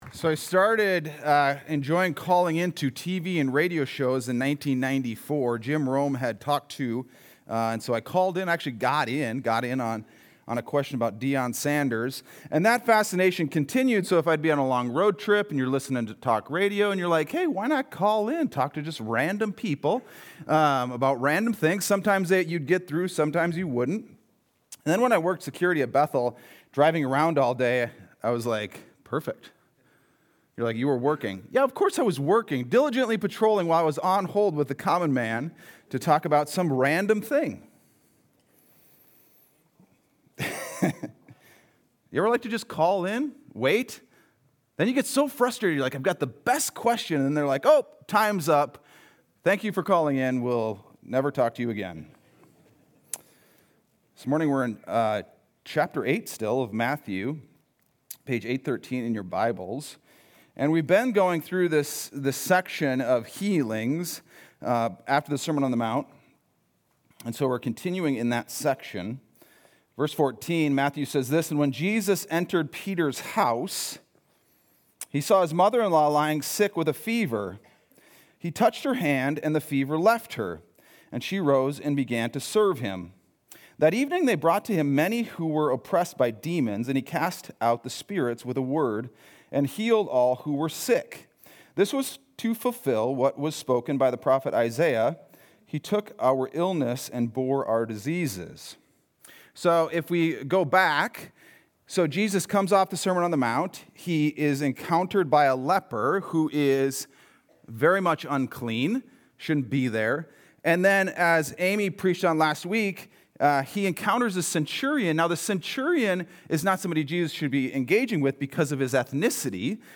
Sunday Sermon: 11-2-25